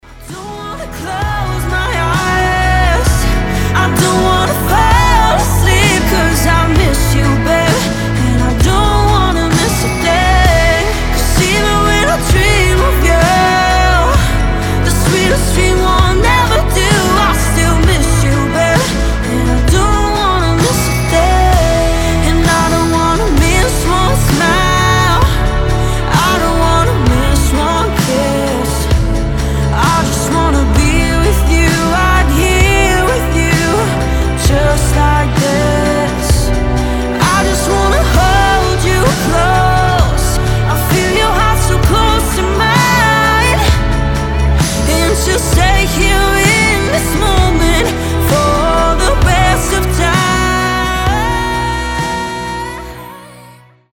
• Качество: 320, Stereo
красивые
Cover
медленные
красивый вокал
баллады